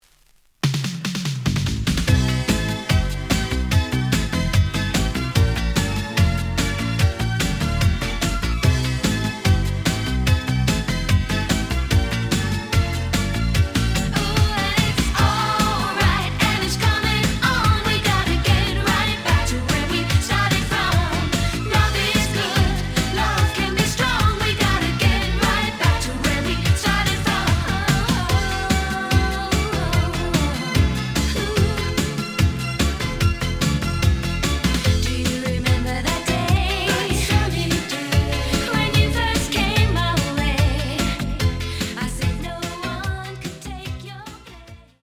試聴は実際のレコードから録音しています。
●Format: 7 inch
●Genre: Soul, 80's / 90's Soul